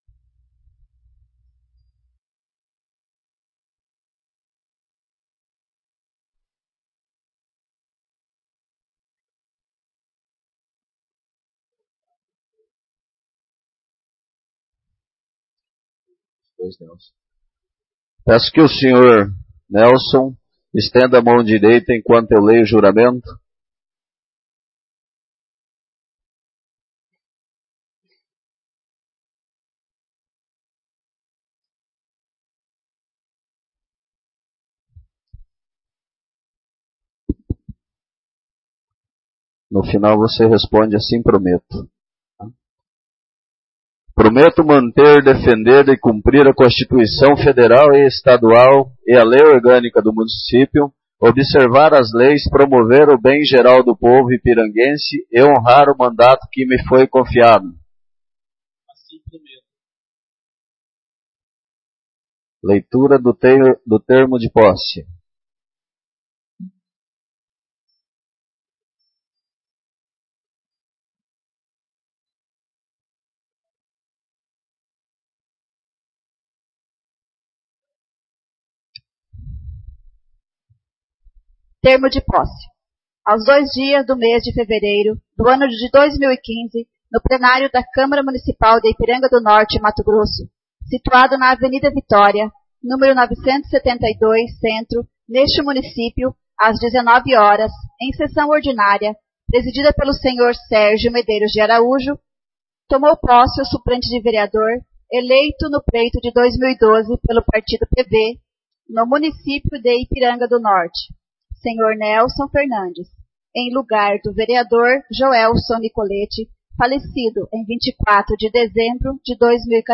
Primeira Sessão Ordinária 1/4 — Câmara Municipal de Ipiranga do Norte
Primeira Sessão Ordinária 1/4